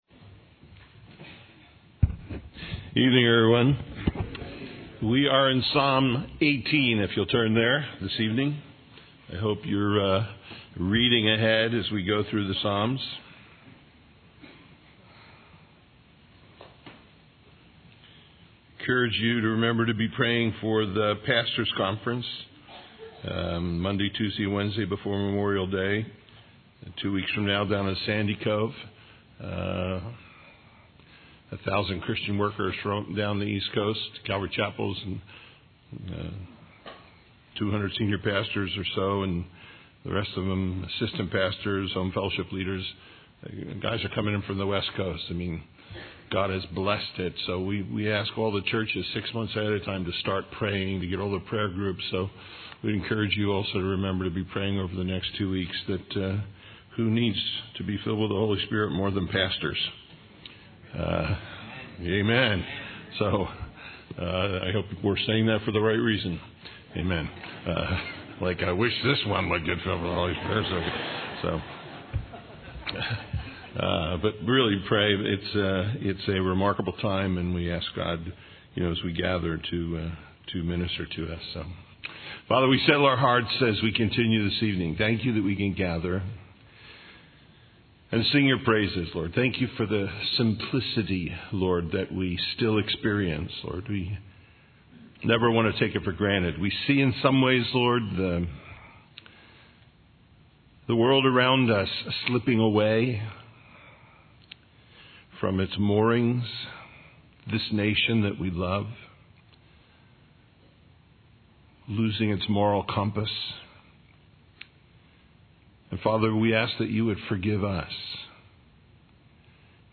Psalms 18:1-18:50 Listen Download Original Teaching Email Feedback 18 I will love thee, O LORD, my strength.